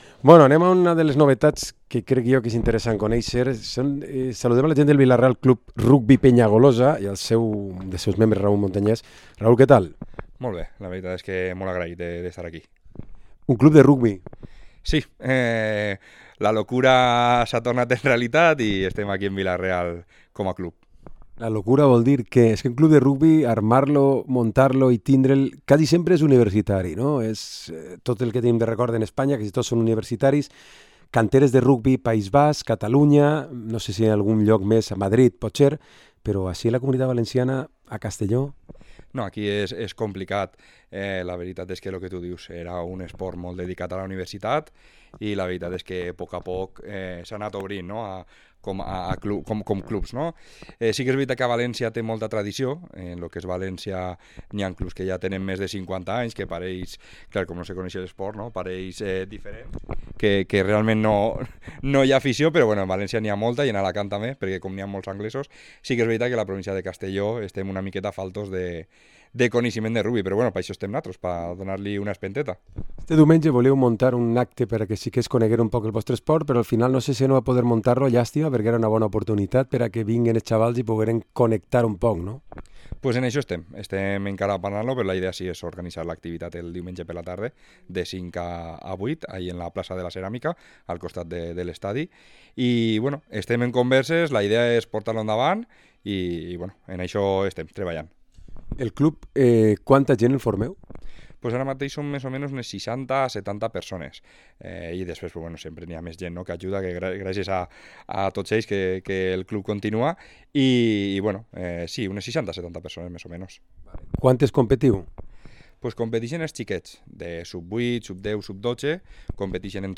Entrevista Vila-Real Club Rugby Penyagolosa